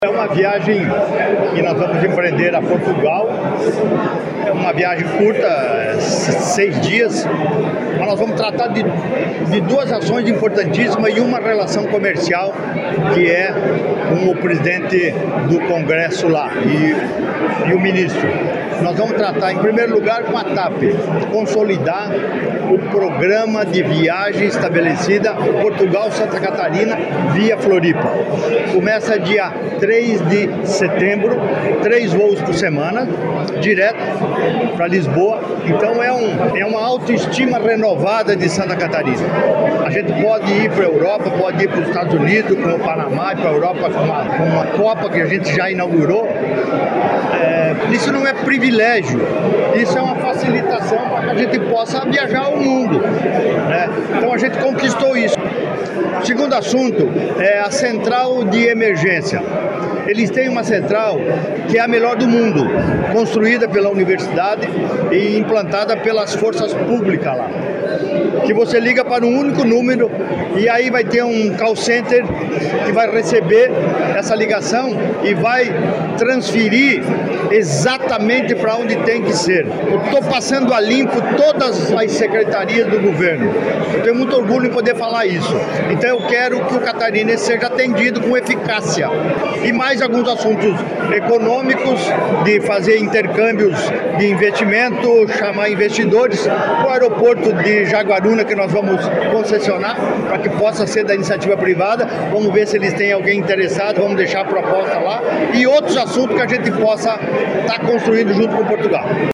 Segundo o governador, a missão vai em busca de investimentos para o estado, de fomento nas relações comerciais e consolidar o programa de viagens: